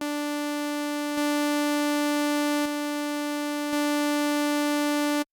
Filter 2: with the filter fully open, the 2-pole low/hi pass modes are quite a bit louder than the 1-pole low/hi pass modes.
Filter 2’s default res is 12, but yes i’m using the AK’s ‘init’ sound with velocity set to ‘off’
Here’s an audio snippet, toggling between 1-pole and 2-pole (louder):